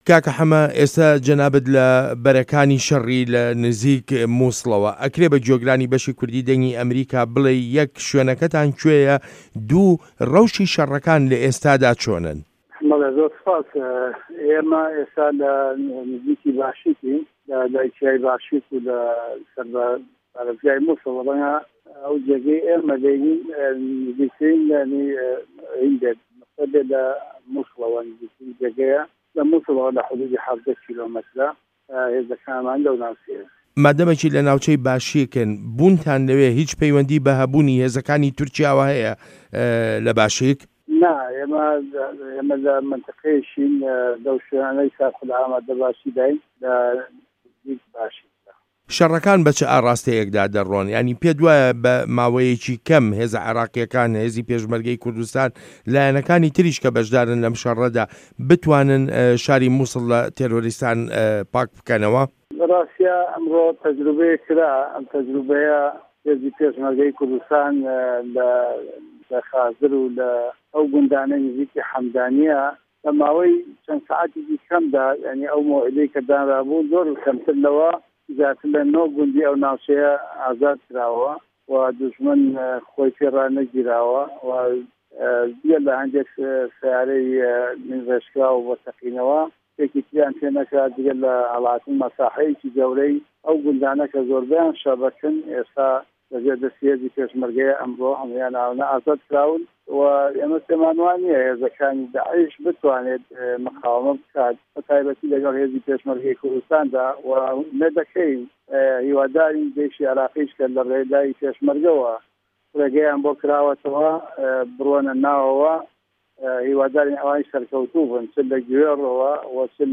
وتووێژ لەگەڵ موحەمەدی حاجی مەحمود